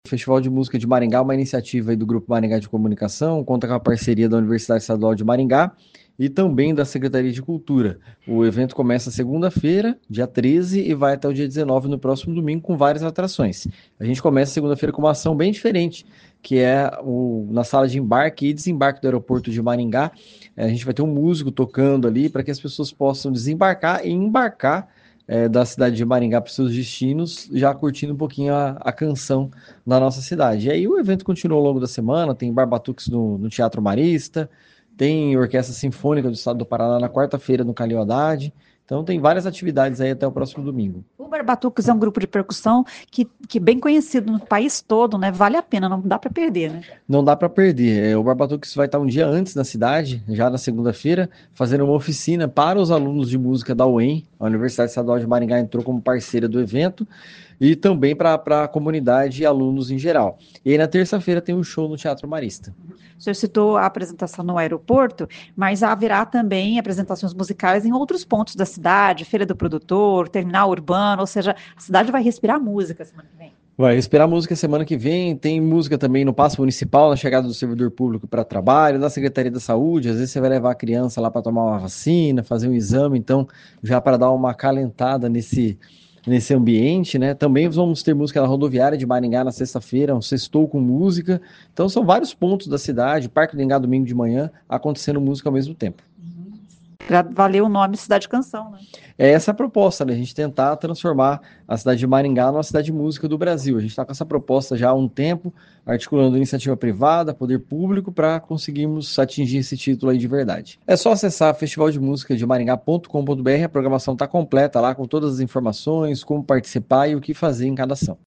Ouça o que diz o secretário de Cultura Tiago Valenciano: